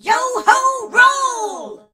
darryl_ulti_vo_04.ogg